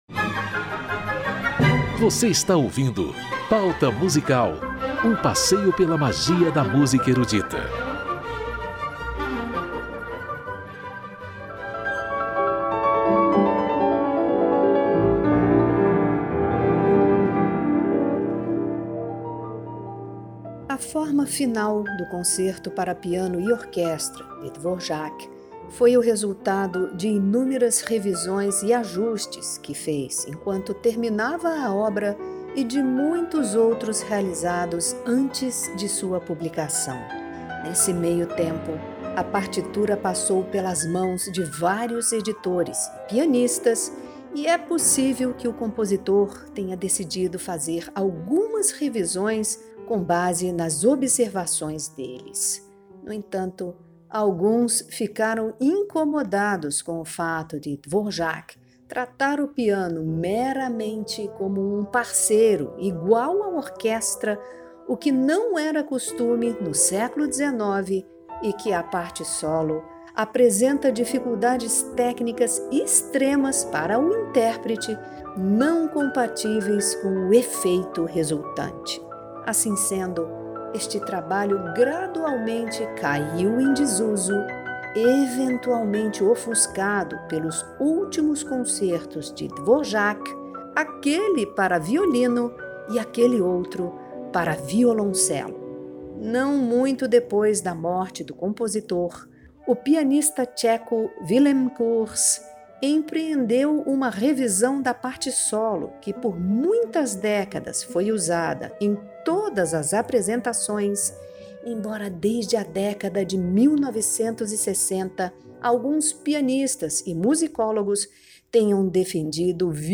concertos orquestrais
interpretam uma mazurca e um concerto para piano.